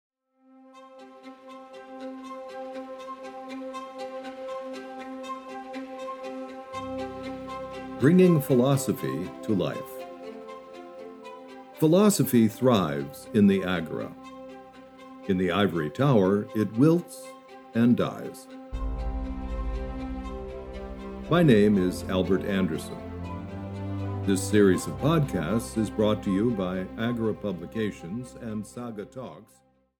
Audio knihaThe Social Media (EN)
Ukázka z knihy